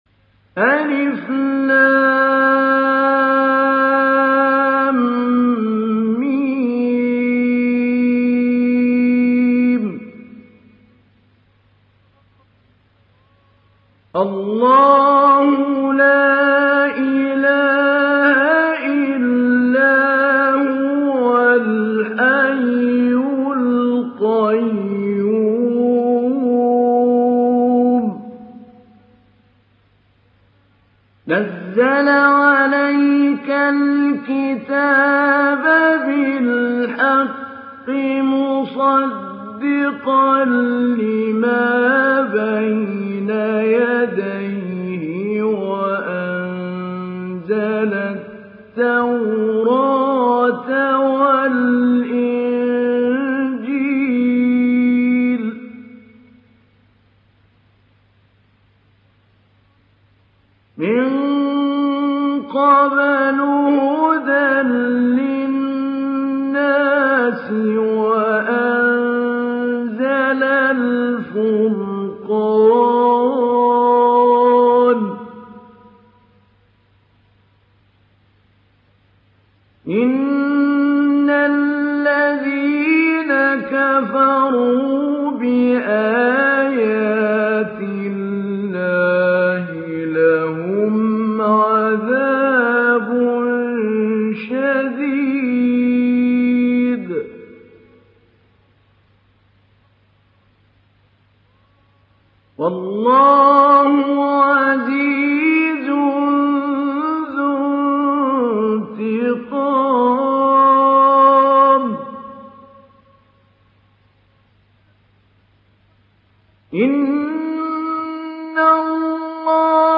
Download Surah Al Imran Mahmoud Ali Albanna Mujawwad